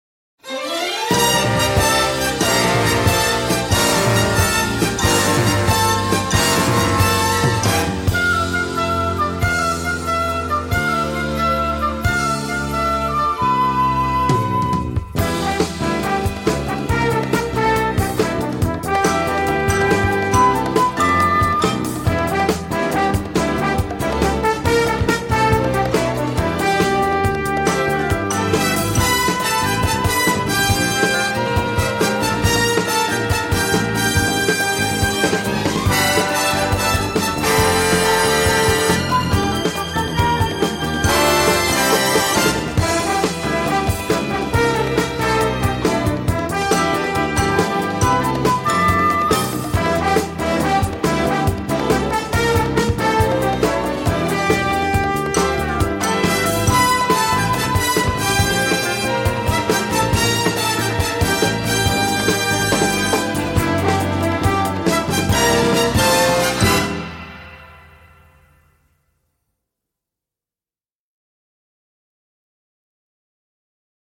Mélodies longues et qui se retiennent bien